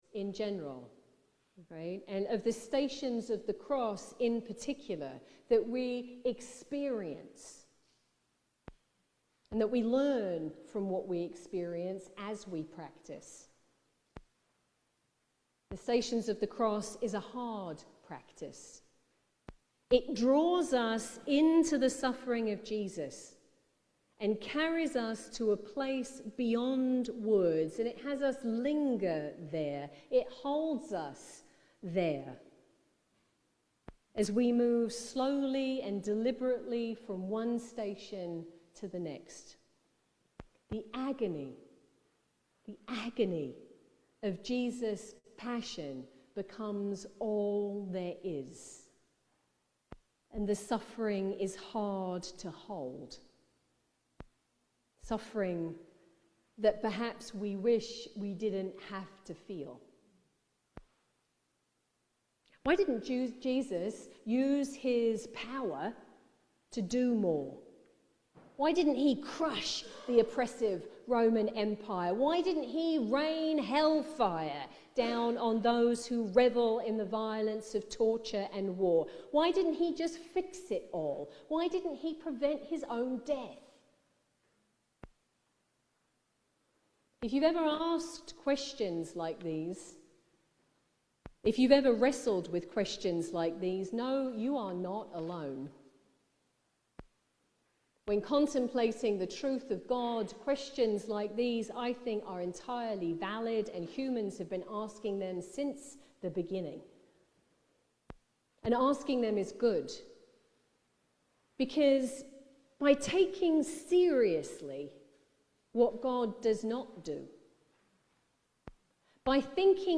The video is missing the first few moments of the sermon because of a technical issue.